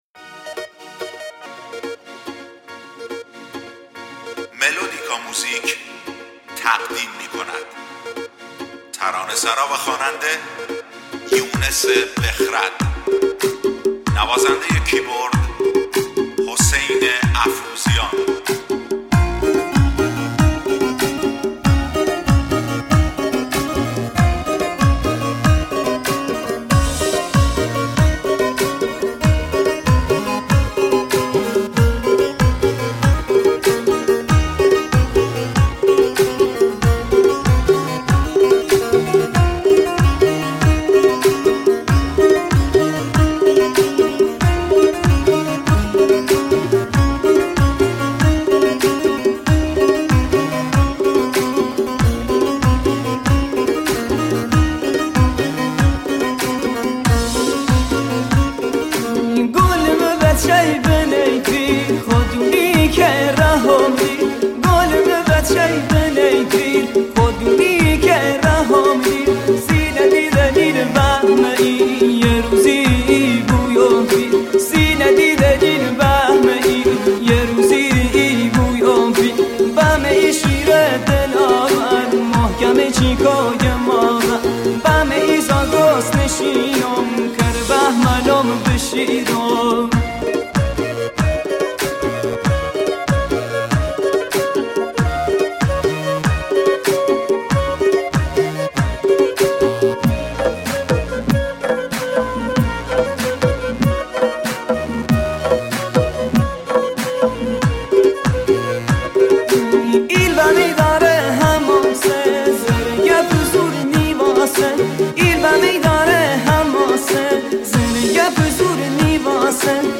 Lori song